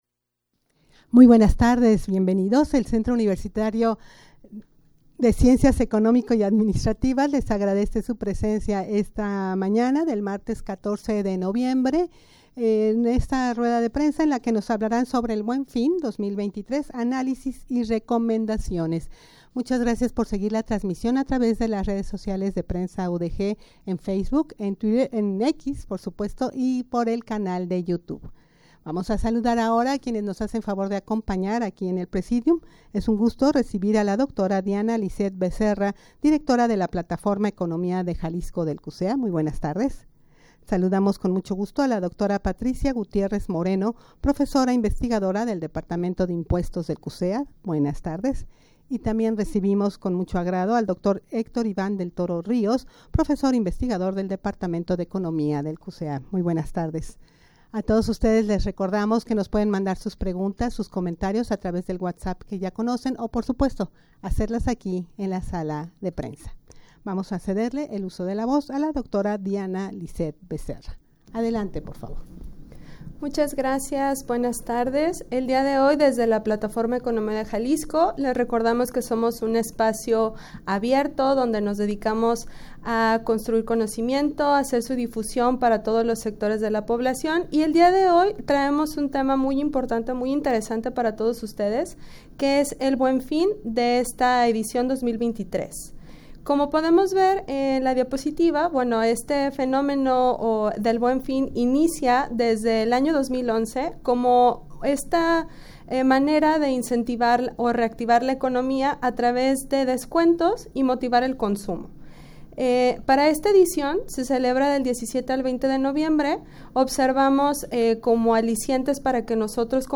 rueda-de-prensa-sobre-el-buen-fin-2023-analisis-y-recomendaciones.mp3